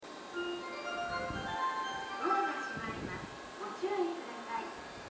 スピーカーはＴＯＡ型が設置されており音質も高音質です。
発車メロディーフルコーラスです。